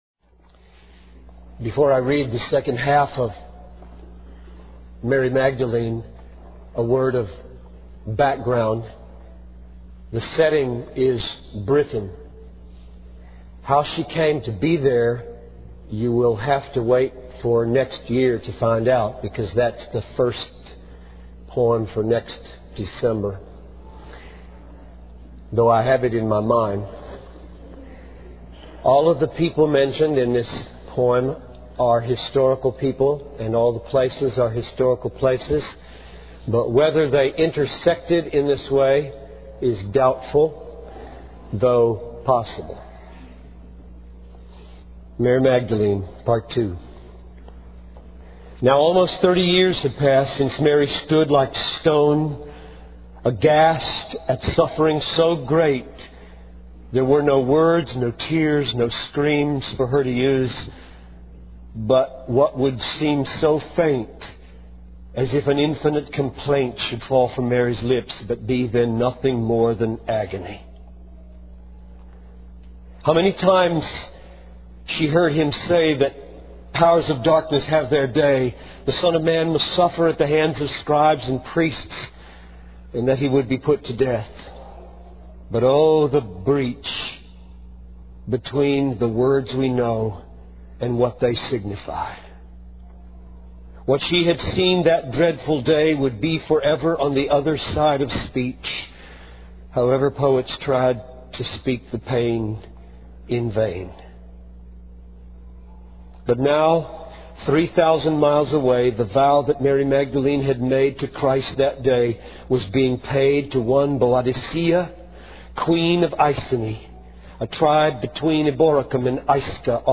In this sermon, the preacher shares the story of Mary Magdalene and her encounter with Jesus.